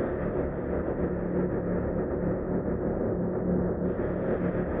SS_CreepVoxLoopA-09.wav